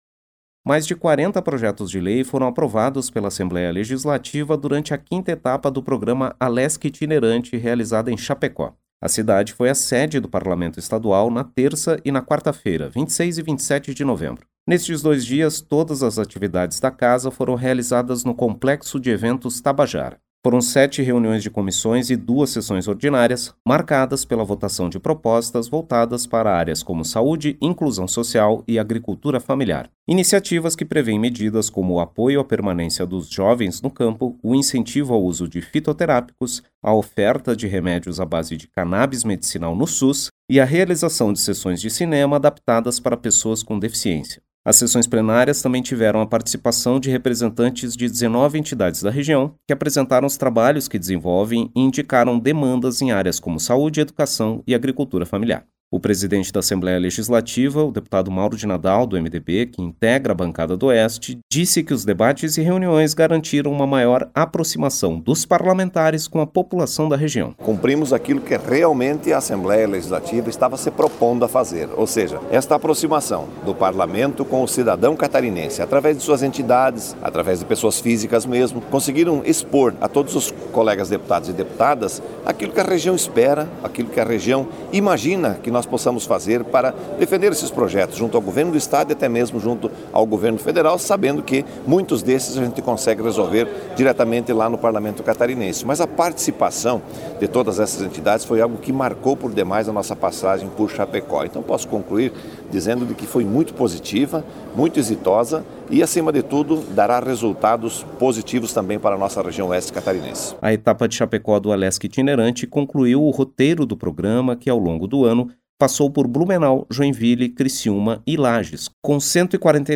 Entrevista com:
- deputado Mauro de Nadal (MDB), presidente da Assembleia Legislativa.